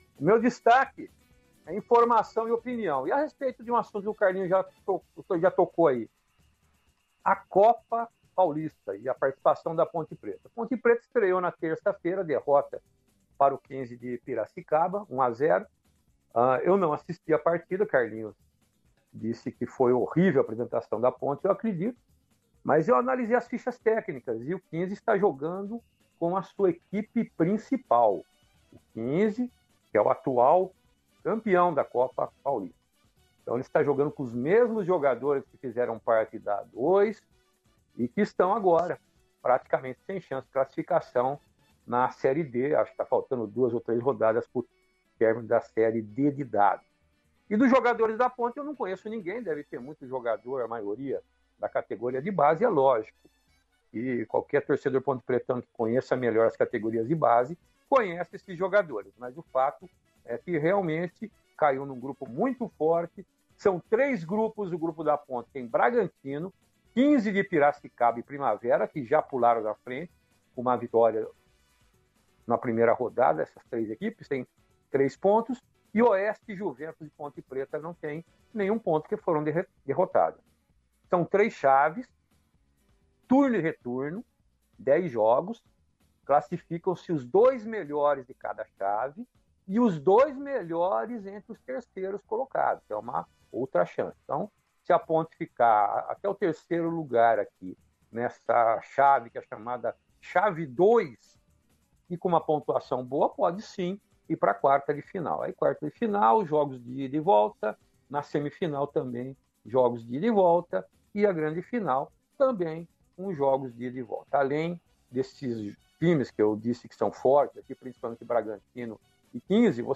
Comentaristas da Rádio Brasil analisam as movimentações da janela de transferências